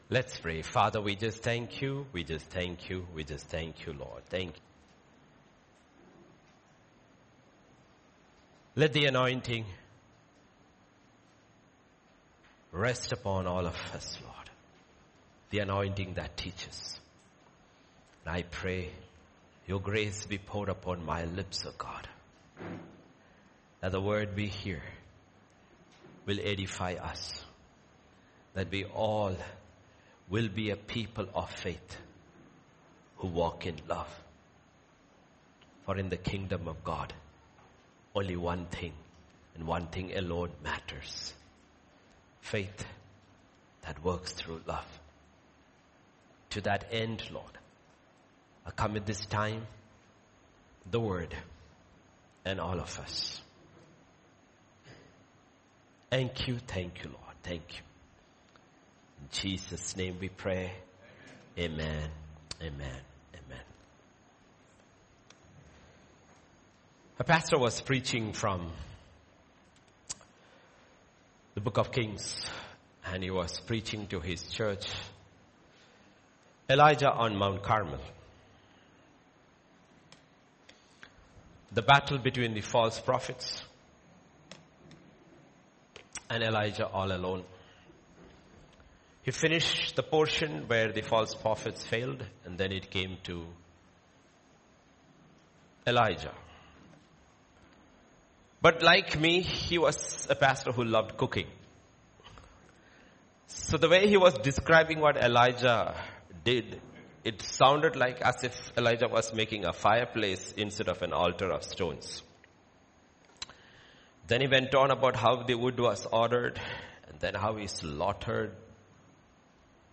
Sermon Preached on Sunday Morning Service.